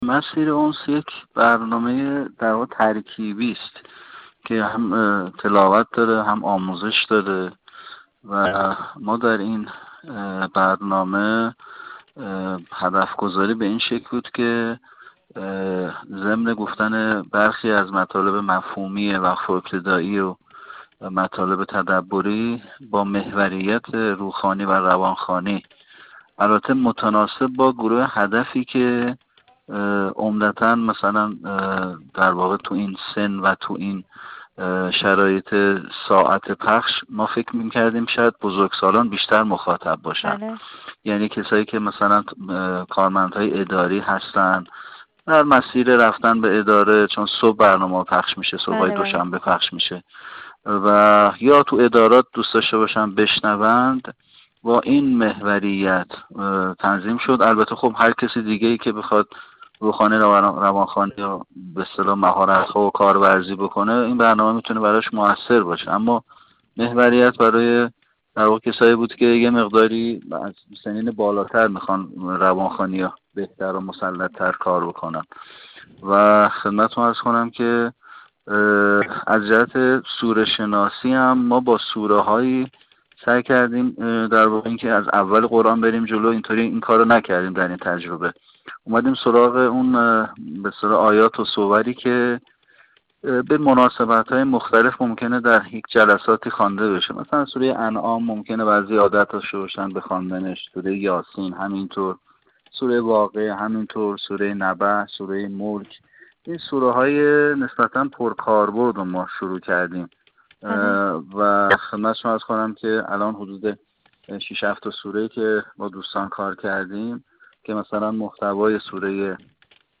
این داور بین‌المللی کشورمان با اشاره به لزوم توجه به محتوای سوره‌های حجرات و لقمان به دلیل دارا بودن مضامین اخلاقی و تربیتی، گفت: ایجاد جذابیت‌های خوانش آیات و قطعات در هر برنامه (که بر روی نکات آموزشی روخوانی و روان‌خوانی آن کار می‌شود)، توسط قراء ایرانی و سایر کشورها از دیگر آیتم‌های این برنامه است.